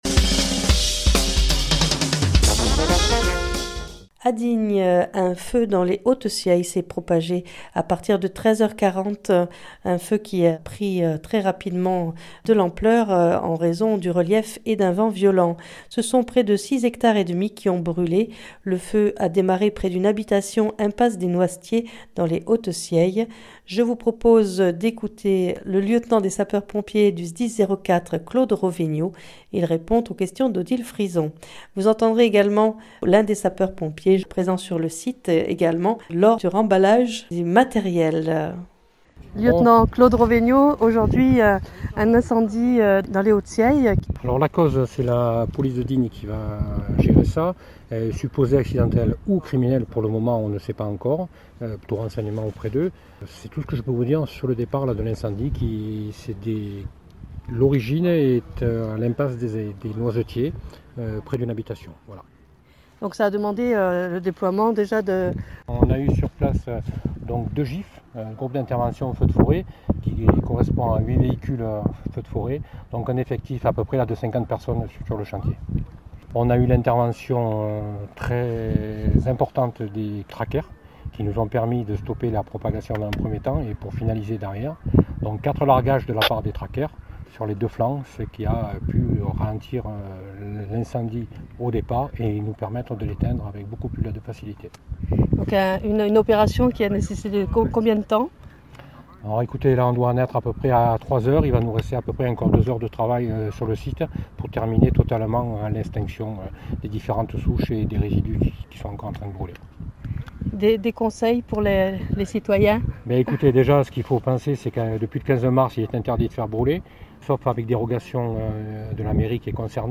Vous entendrez également l'un des sapeurs pompiers lors du remballage du matériel d'incendie.